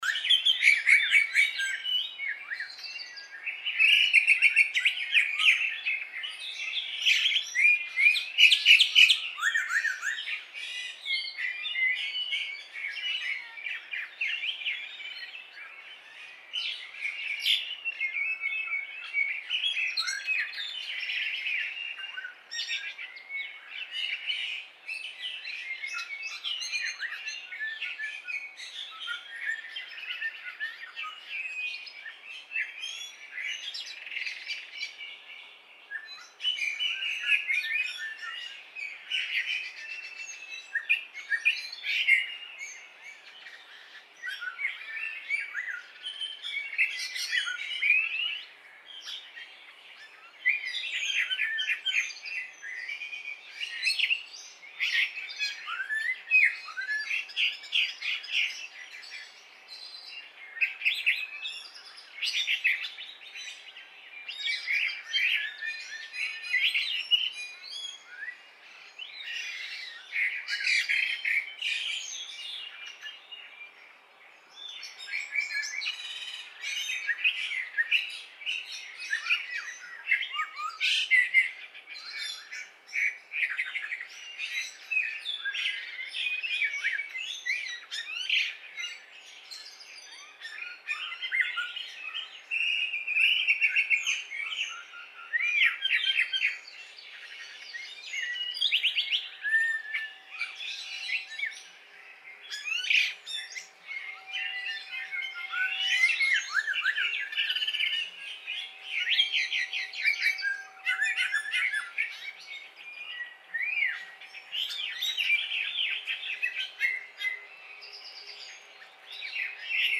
Chinese Blackbird
Babbling.
Turdus mandarinus